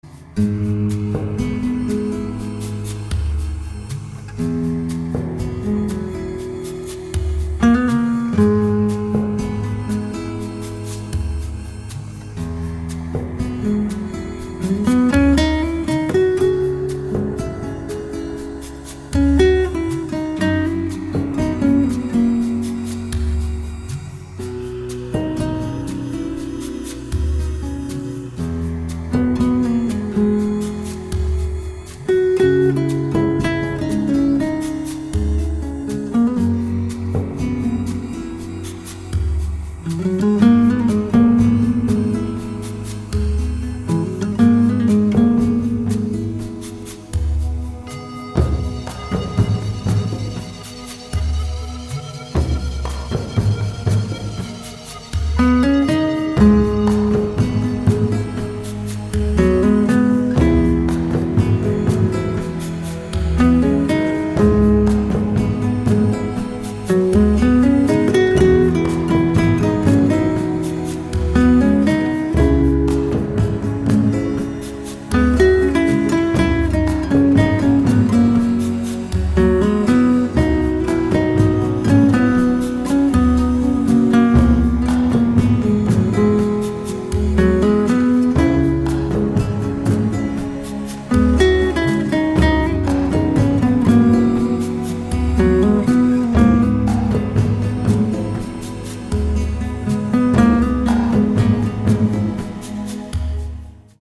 • Качество: 160, Stereo
гитара
красивые
спокойные
без слов
инструментальные
Blues